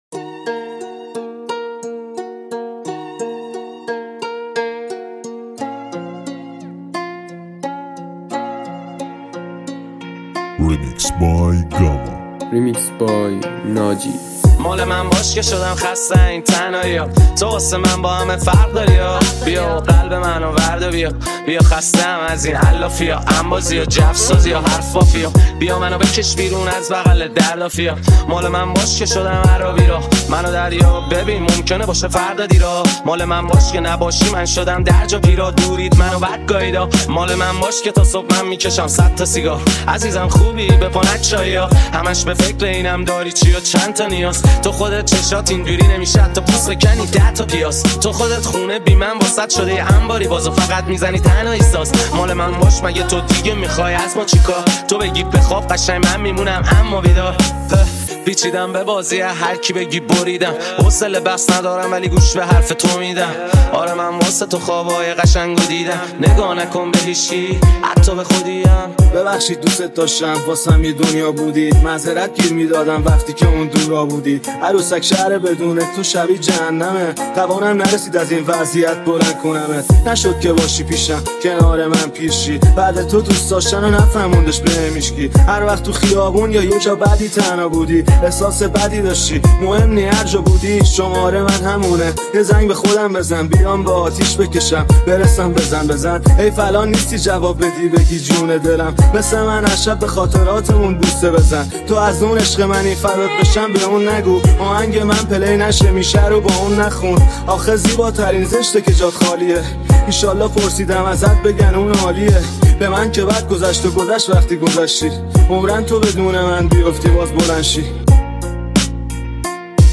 ریمیکس رپ جدید